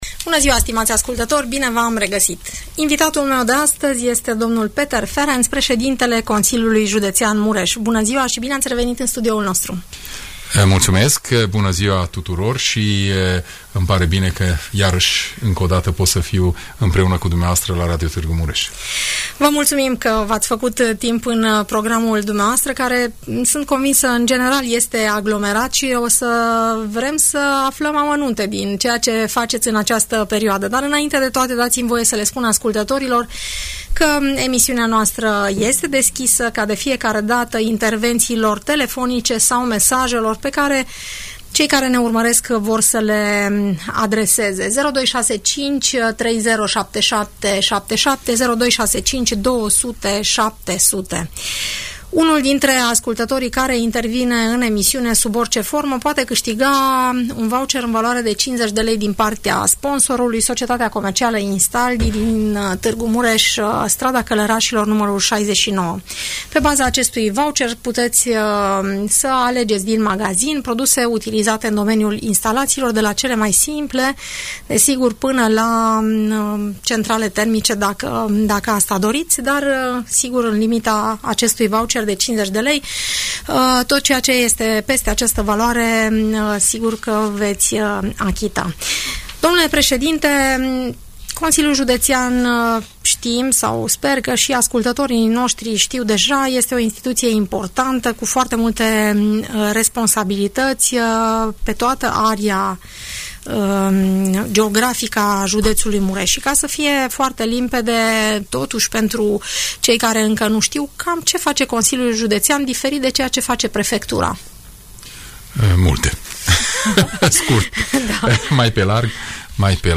Peter Ferenc, președintele Consiliului Județean Mureș, explică în emisiunea „Părerea ta”, care sunt proiectele în curs de finalizare, dar și cele care urmează să fie demarate la nivel de județ.